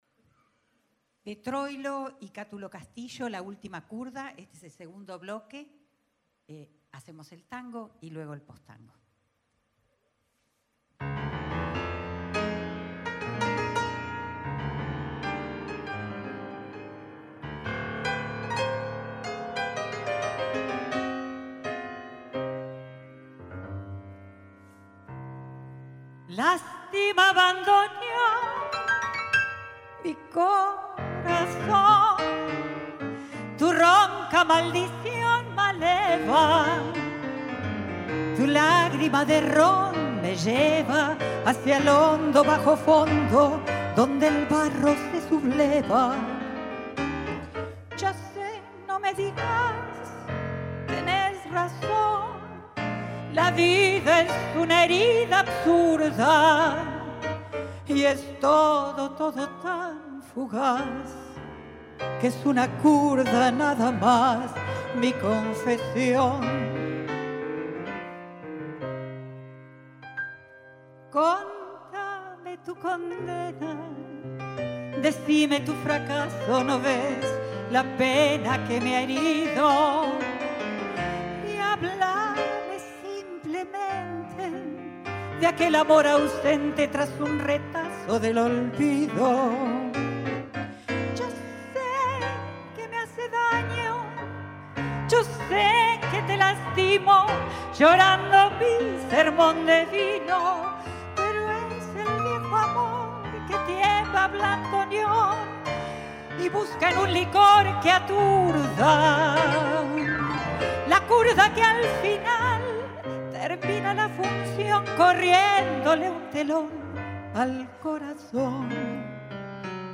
Temporada de Música de Cámara 2026.
Voz
Piano
Grabación realizada por el equipo de exteriores de las Radios Públicas el 9 de abril de 2026 en el Auditorio Vaz Ferreira.